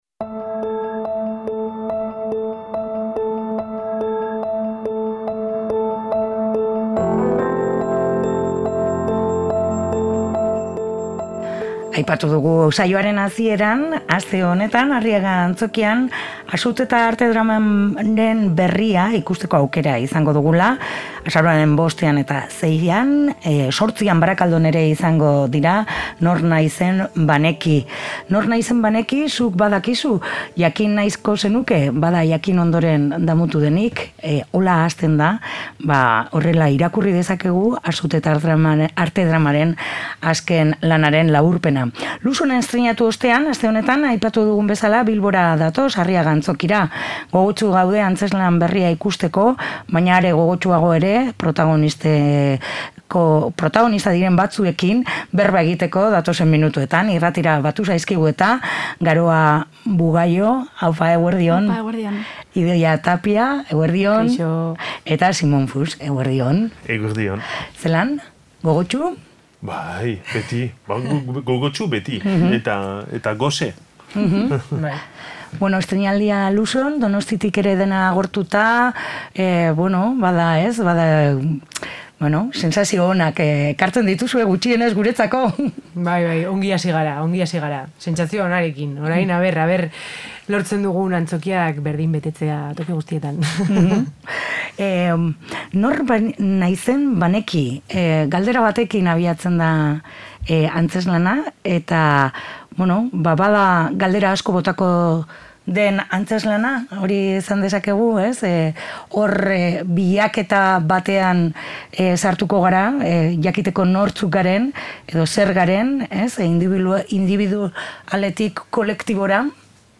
Luhson estreinatu ondoren, aste honetan Bilbora iritsiko dira, bi egunez Arriaga Antzokian izango dira. Gogotsu gaude antzezlan berria ikusteko, baina are gogotsuago gaur gurekin batu diren protagonista bietako birekin solasean aritzeko